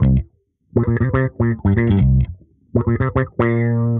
Index of /musicradar/dusty-funk-samples/Bass/120bpm